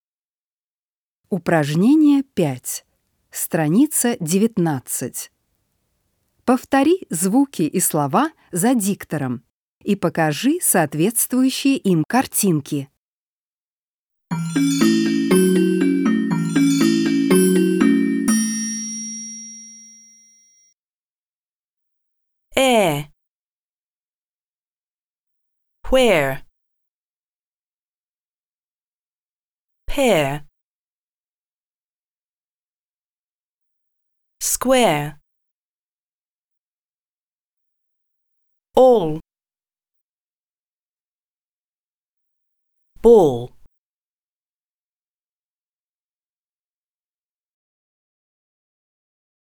5. Повтори звуки и слова за диктором и покажи соответствующие им картинки.